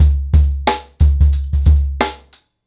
TrackBack-90BPM.3.wav